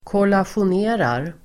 Ladda ner uttalet
Uttal: [kålasjon'e:rar]